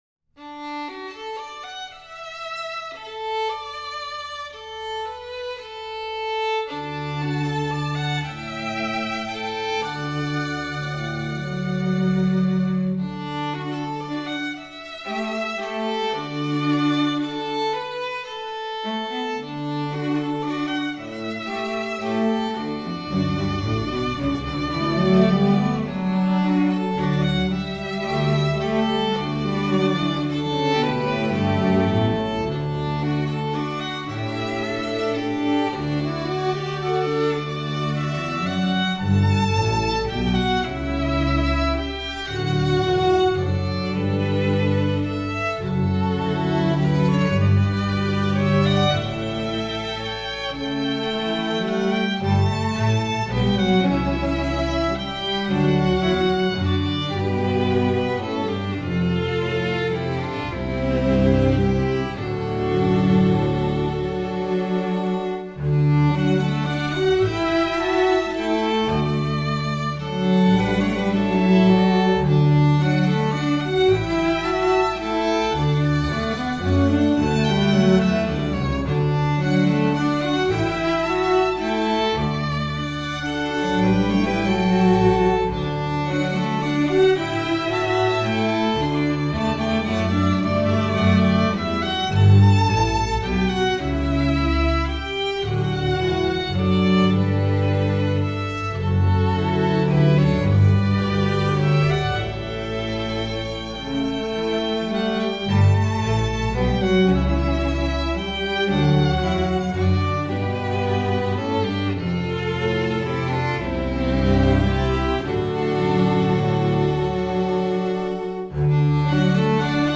Genre: Classical Chamber Music live Orchestral.